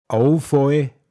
Wortlisten - Pinzgauer Mundart Lexikon
Abfall Åufåi, m.